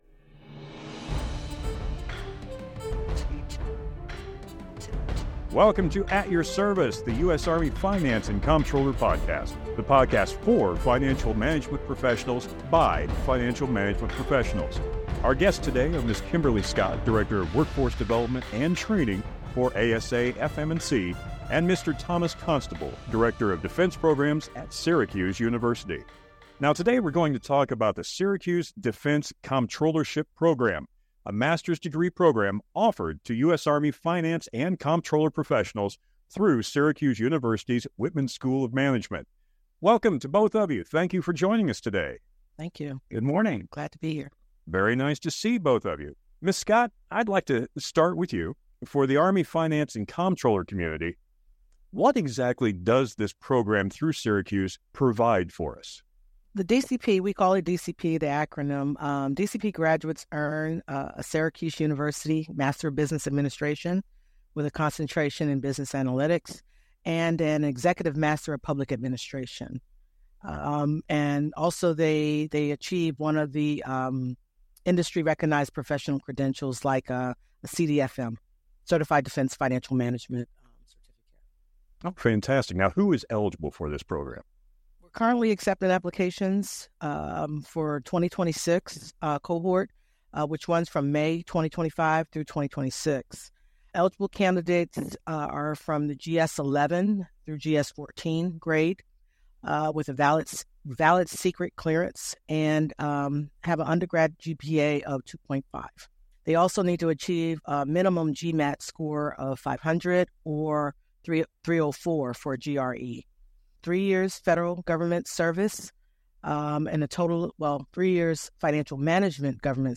This episode is taken from the FC Profession Series full-length video which can be found on DVIDS.